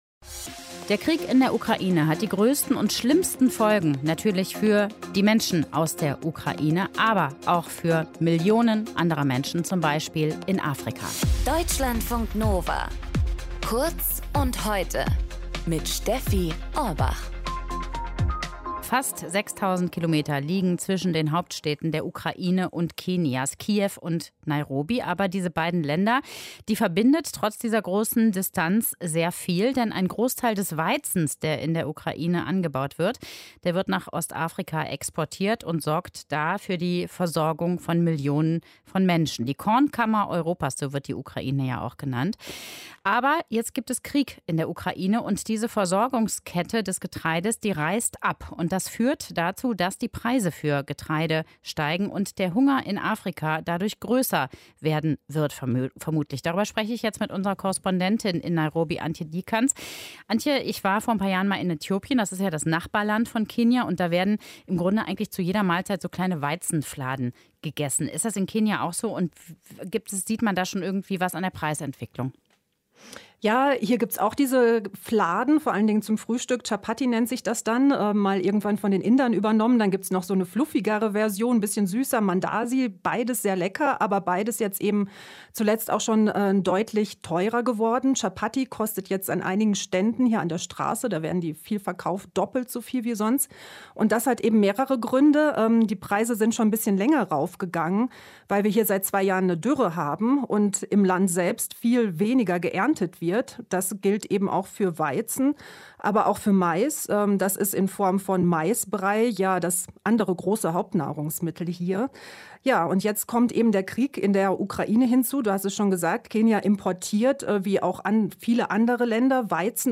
Wegen des Krieges steigen die Preise für Weizen, aus dem Brot gemacht wird. Unsere Korrespondentin berichtet, aus dem Armenvierteln sei zu hören: "Das Essen, das uns satt macht, wird jetzt unerschwinglich."